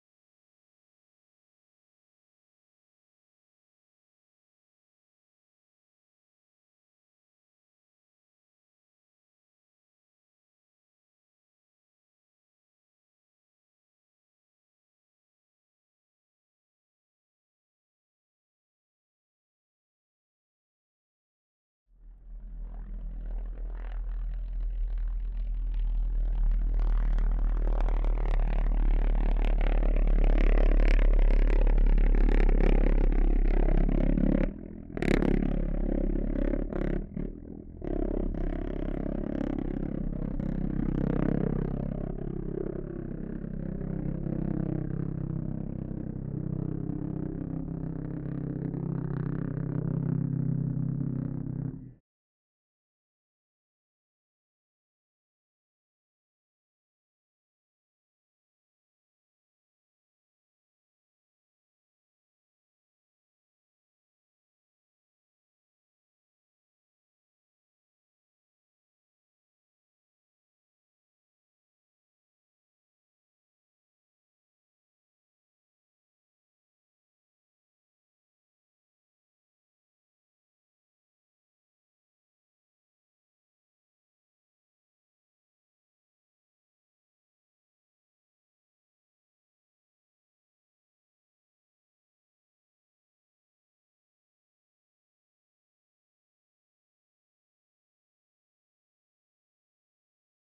Pulse Jet Snowmobile, t2, Ext, By, Distant, Holophone.ogg